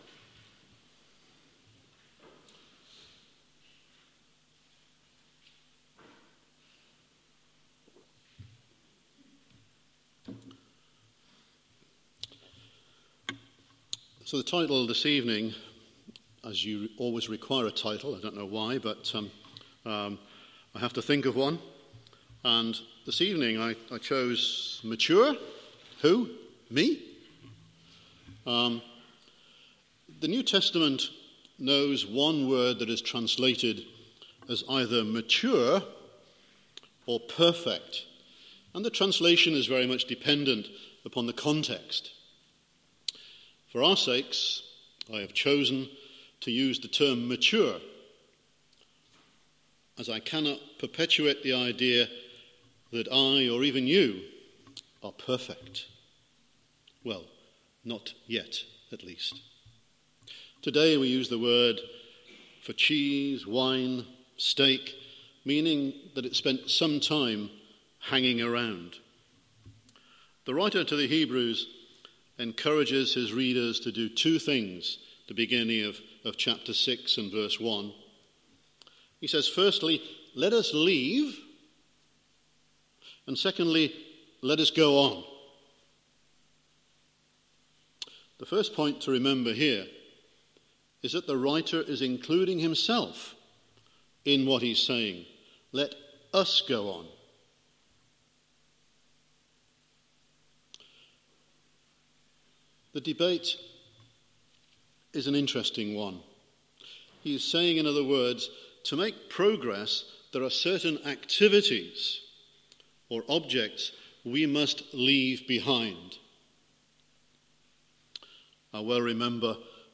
Guest Speaker , Evening Service